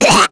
Nia-Vox_Damage_kr_01.wav